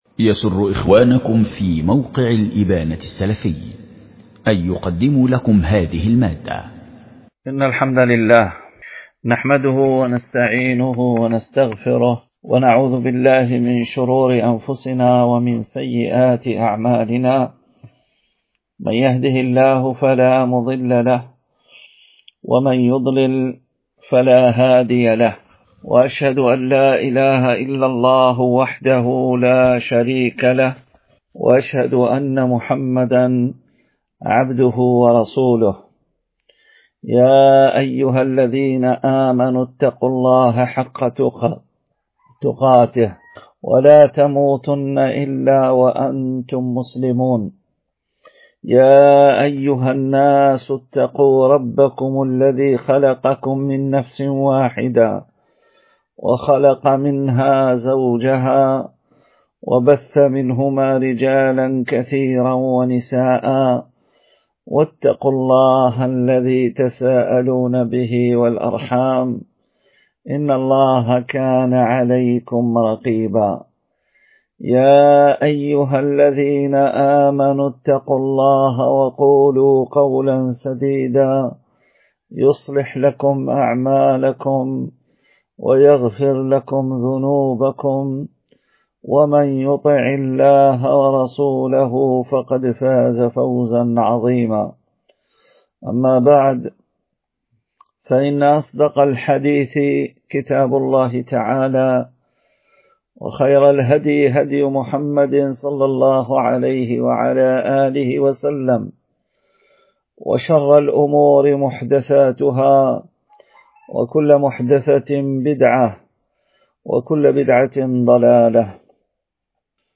ضمن فعاليات دورة الإمام مالك بن أنس العلمية